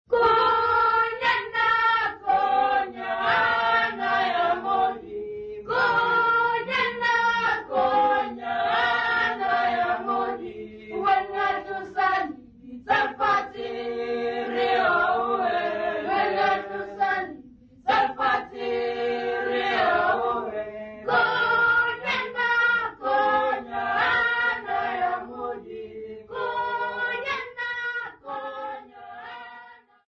Church music workshop participants
Folk music
Sacred music
field recordings
New church music accompanied by drum